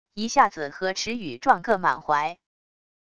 一下子和池羽撞个满怀wav音频生成系统WAV Audio Player